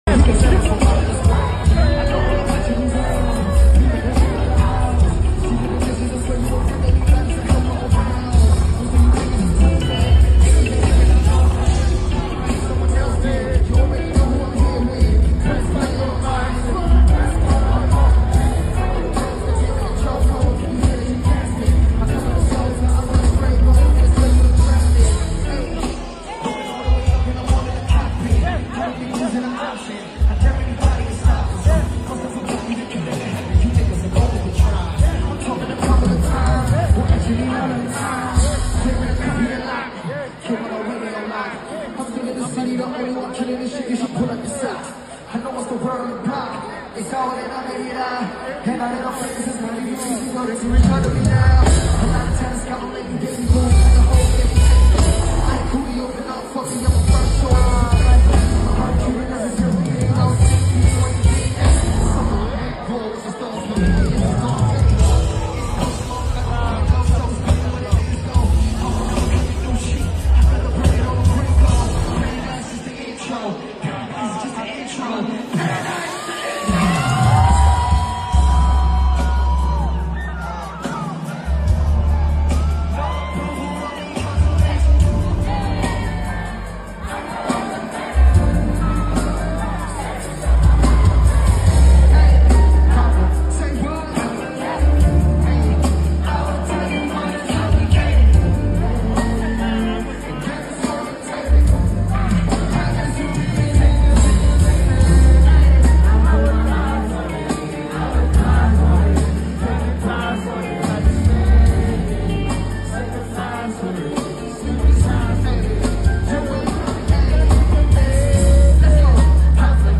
PERFOMS T TRVIS SCOTT CONCERT🔥🔥 mp3 download